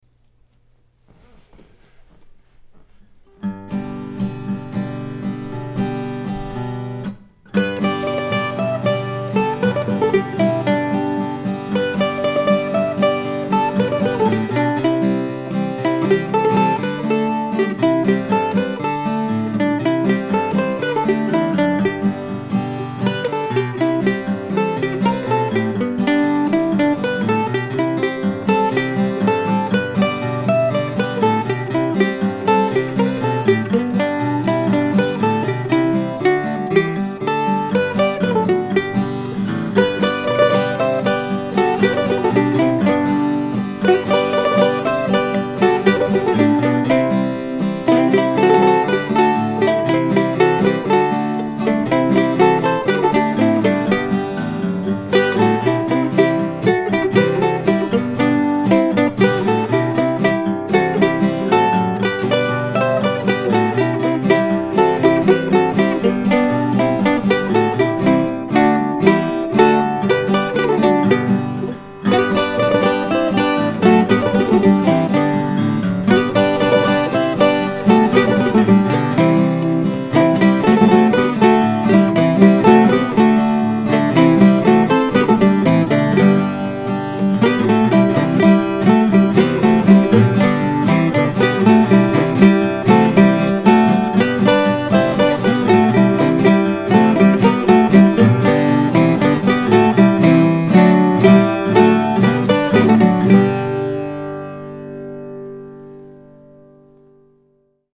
Finally, last week sometime it found its way down to G major and that's how you'll see and hear it today.